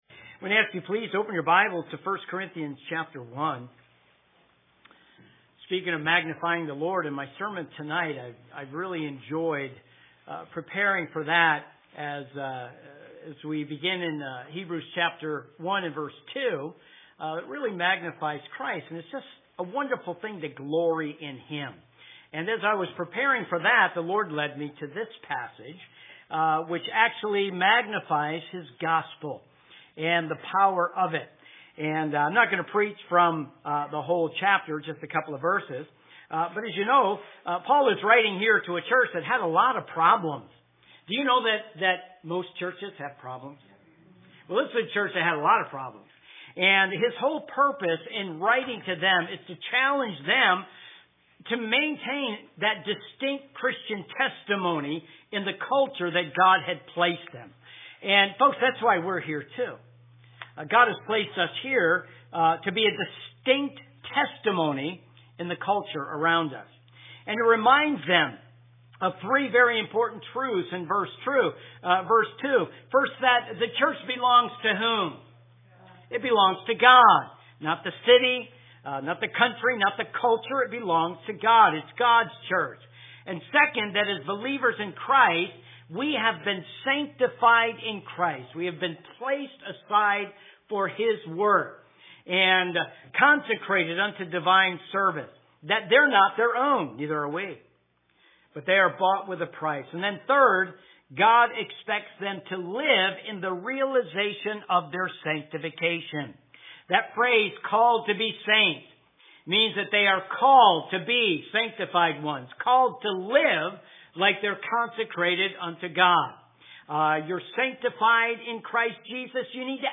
AM Messages